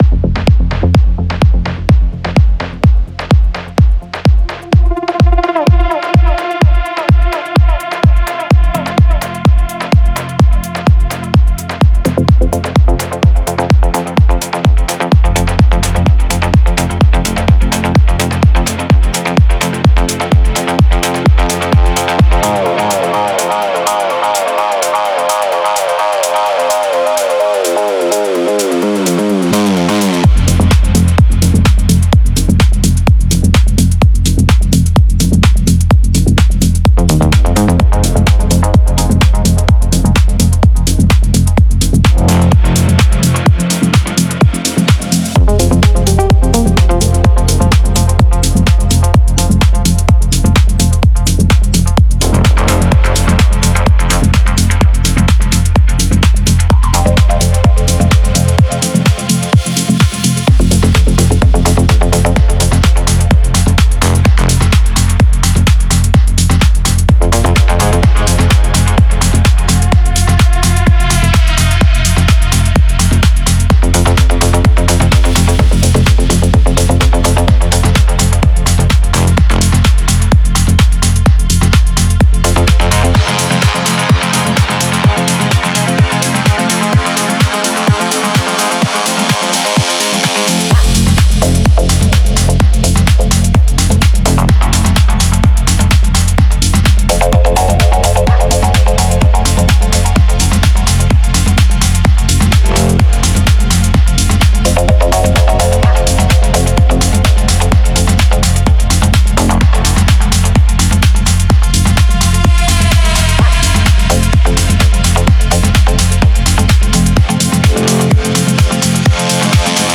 the high and mids to high
Genre Melodic